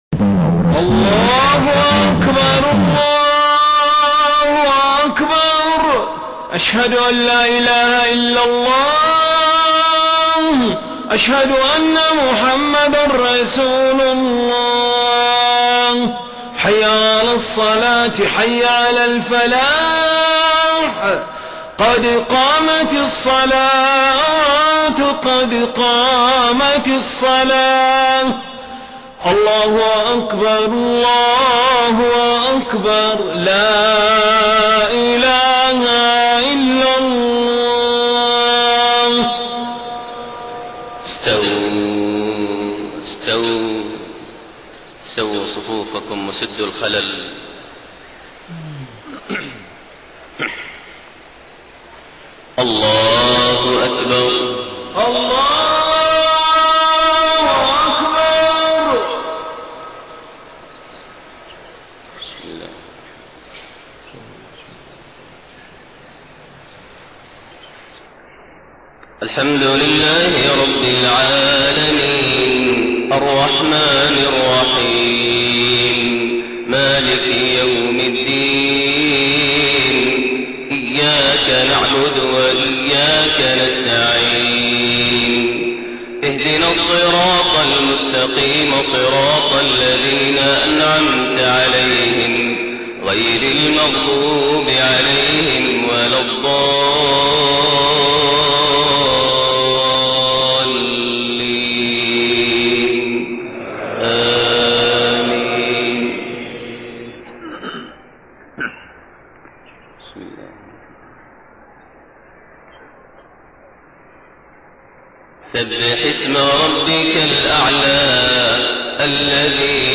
صلاة الفجر 8 ذو الحجة 1432هـ سورة الأعلى و الغاشية > 1432 هـ > الفروض - تلاوات ماهر المعيقلي